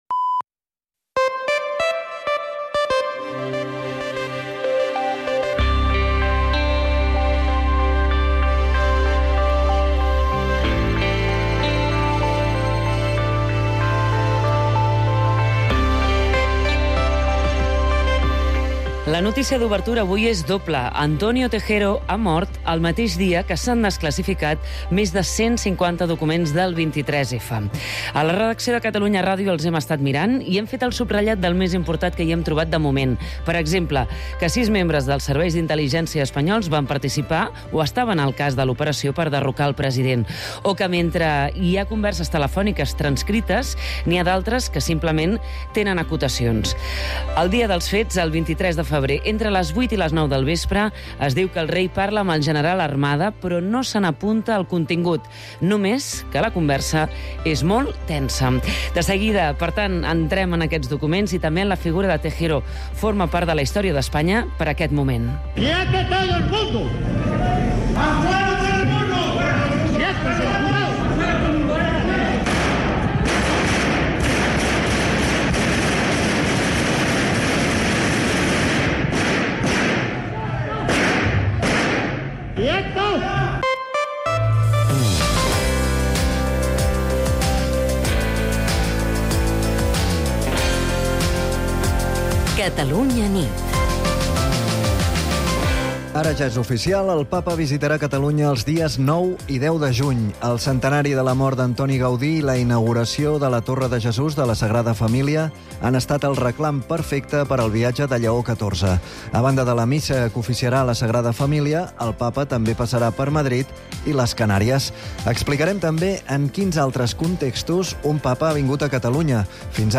l’informatiu nocturn de Catalunya Ràdio